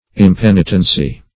Impenitency \Im*pen"i*ten*cy\, n.